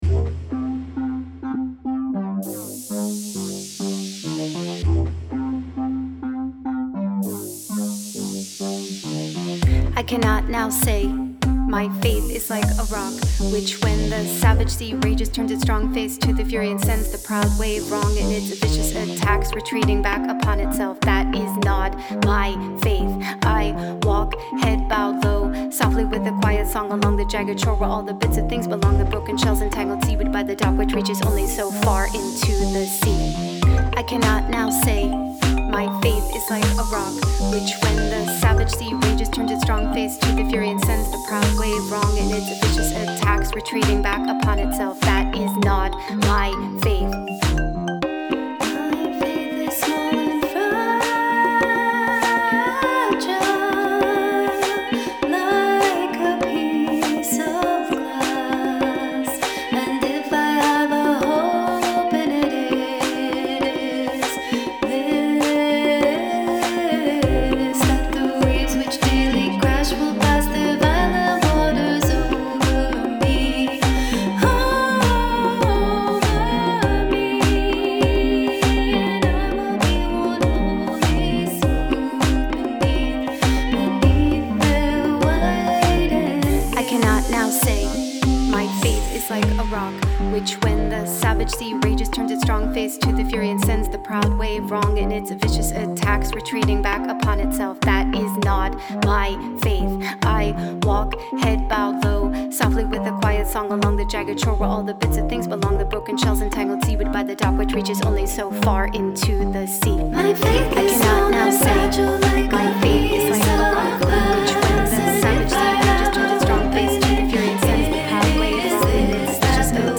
Music: Singles